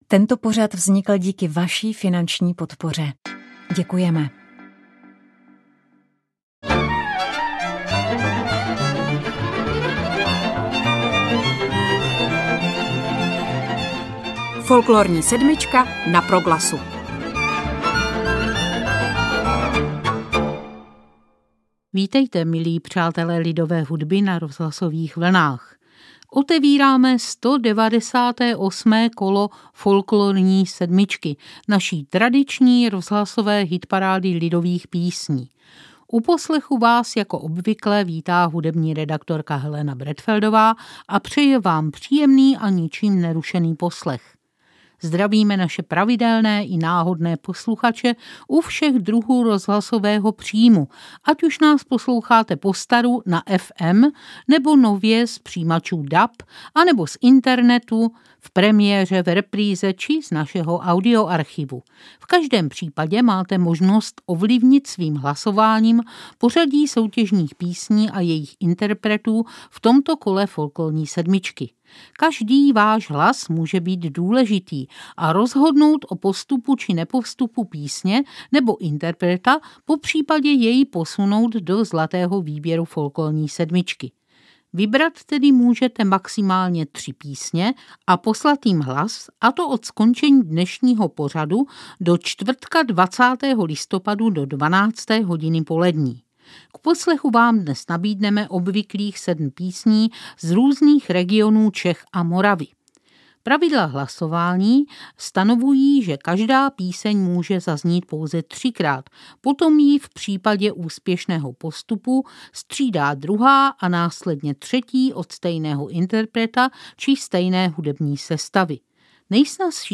A je to tady! Pomyslná fanfára pro vítěze vítá – již podruhé! – Horáckou muziku ze Žďáru nad Sázavou mezi ty nejúspěšnější kapely v naší folklorní hitparádě.